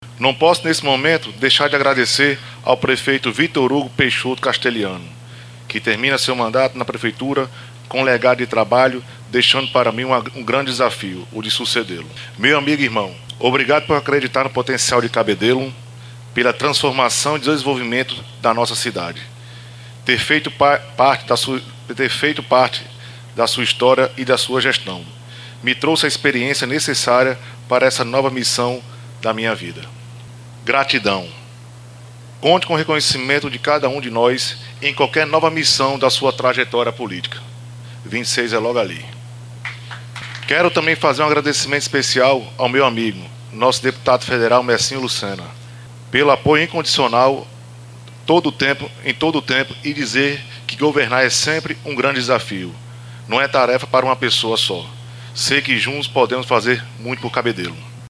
Os comentários de André Coutinho foram registrados pelo programa Correio Debate, da 98 FM, de João Pessoa, nesta quinta-feira (02/01).